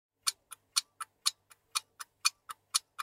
minigame_clock.mp3